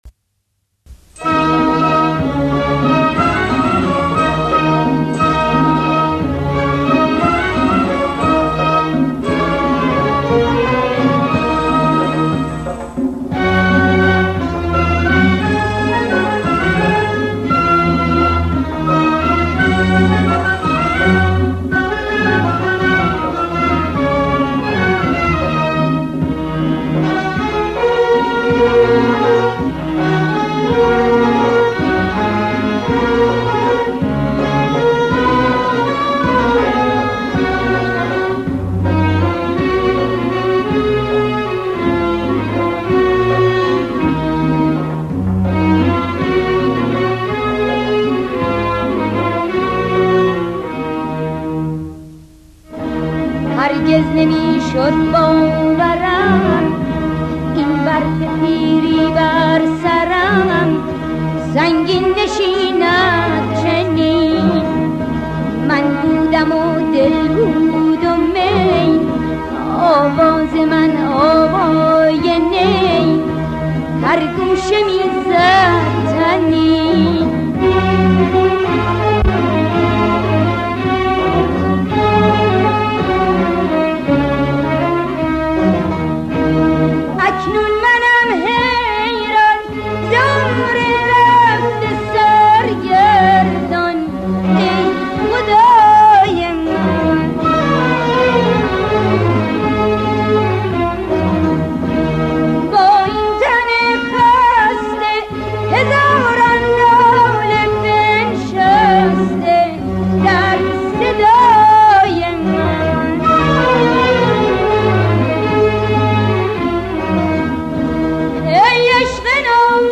دستگاه: شور